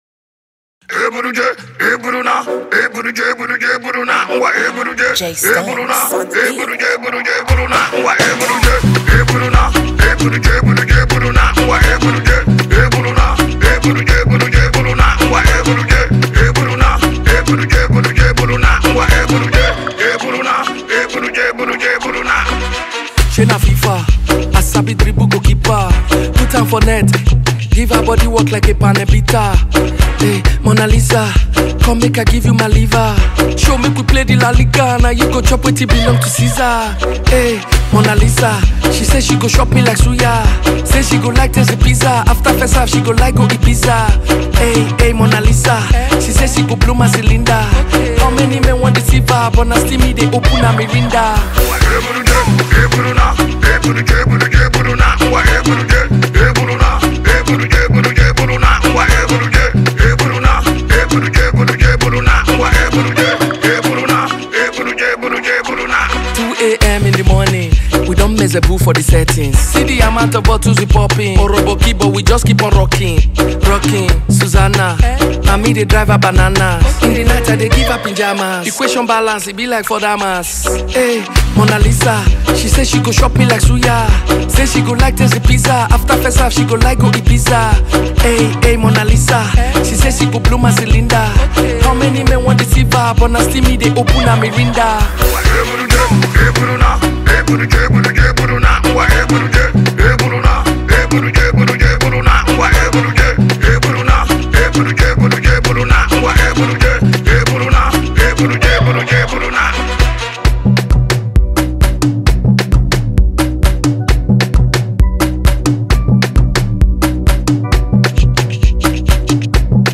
a vibrant record filled with energy, melody, and rhythm